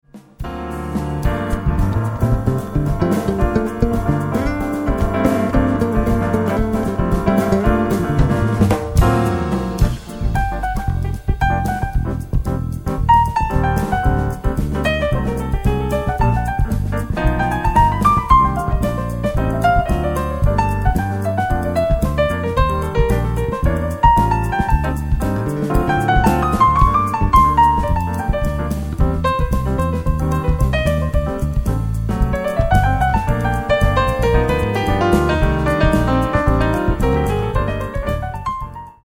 メランコリックなボサジャズ